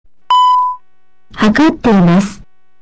「ポン」とブザーが鳴り、 「測っています」と音声案内されます。